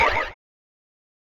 Perc (Jungle).wav